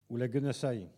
Enquête Arexcpo en Vendée
Collectif-Patois (atlas linguistique n°52)
Catégorie Locution